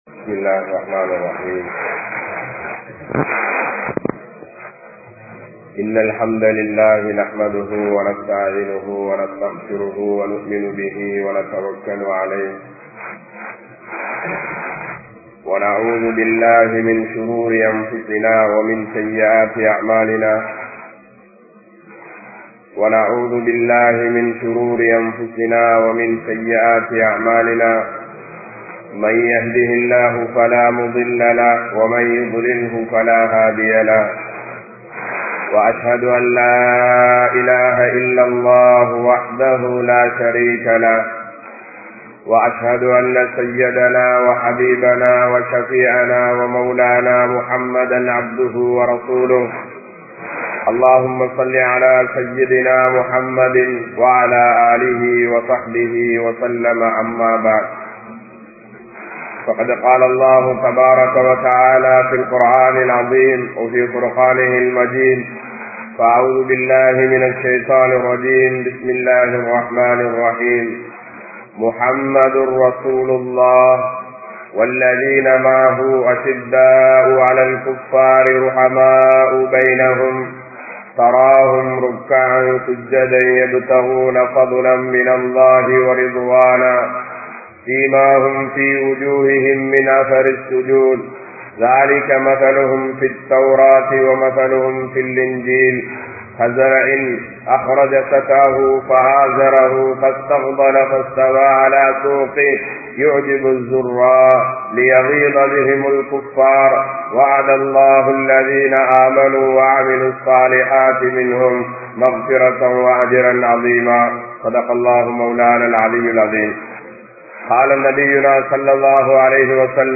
Sahabaakkal Entra Uththamarhal (ஸஹாபாக்கள் என்ற உத்தமர்கள்) | Audio Bayans | All Ceylon Muslim Youth Community | Addalaichenai
Manar Jumua Masjith